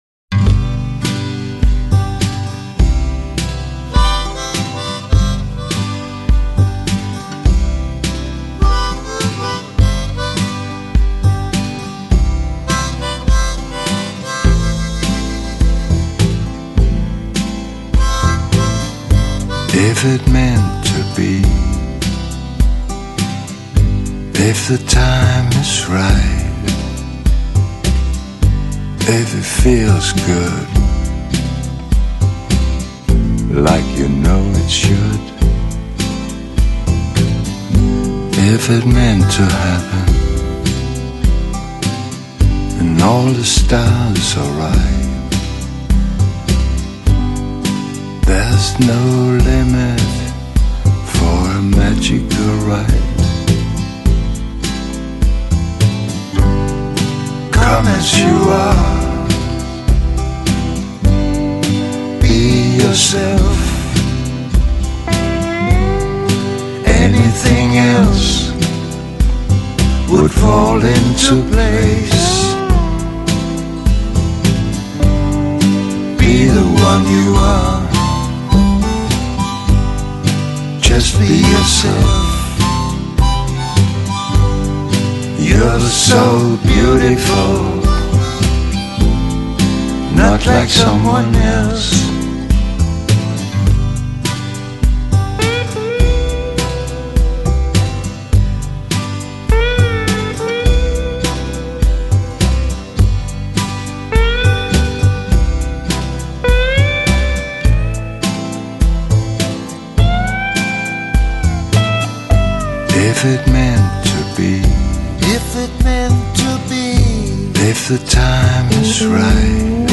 Жанр: Electronic, Downtempo, Synth-pop